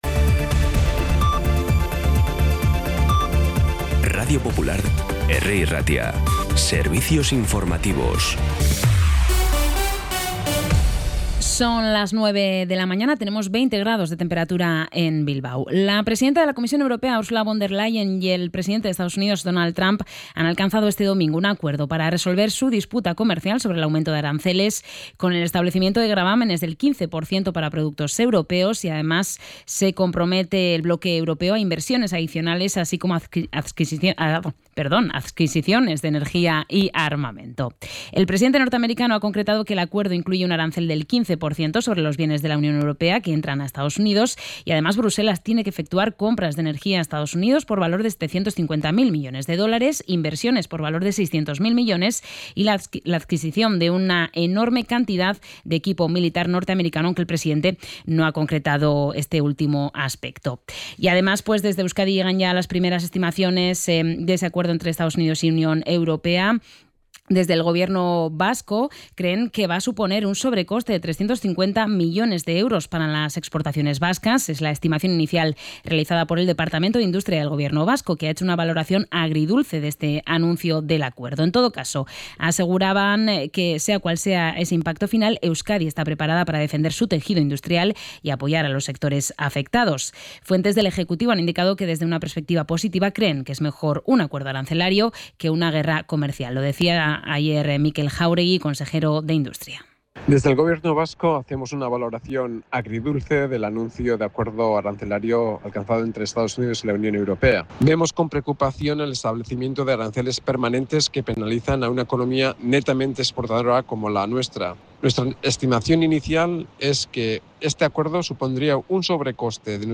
Información y actualidad desde las 9 h de la mañana